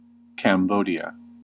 ^ /kæmˈbdiə/
En-us-Cambodia.ogg.mp3